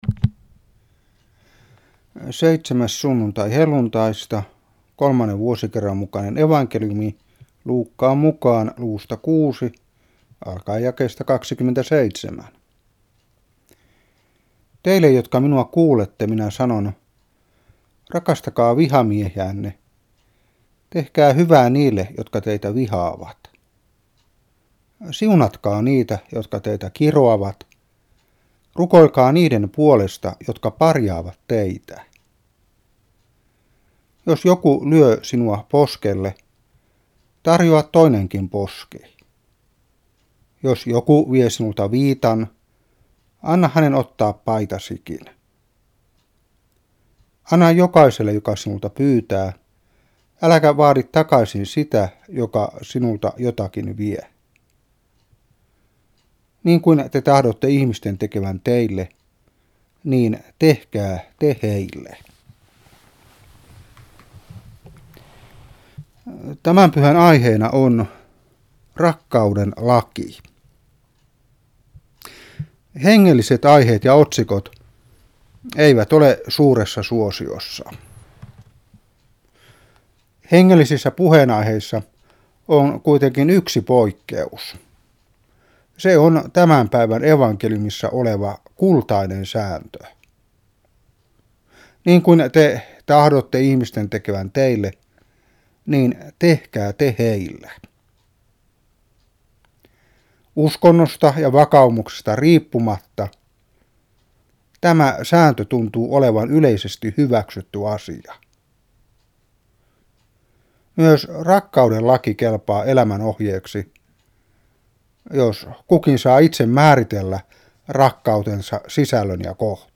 Saarna 2015-7.